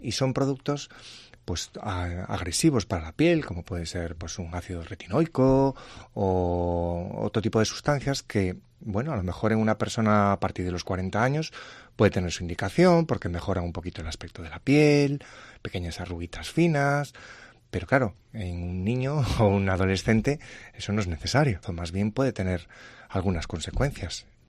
en los estudios de Cope Ourense los riesgos del uso de cosméticos